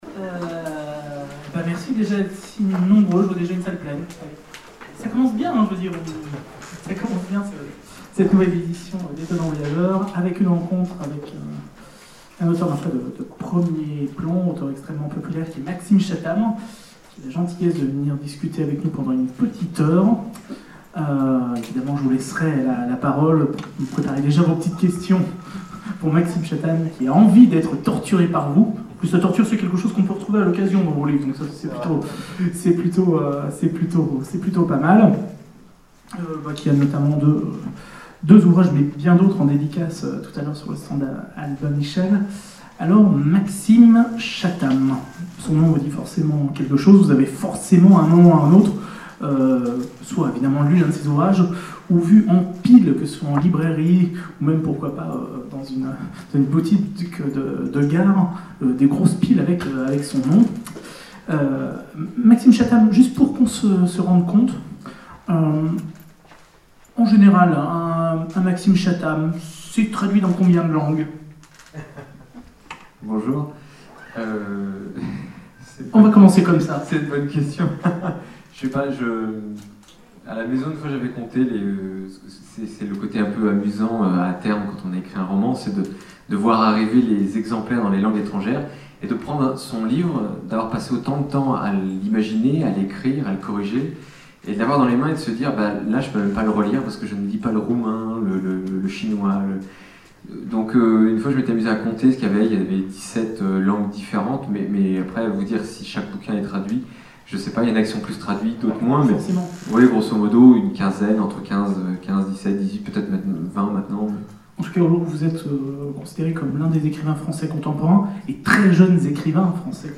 Etonnants Voyageurs 2012 : Conférence Le Monde de Maxime Chattam
Conférence
Rencontre avec un auteur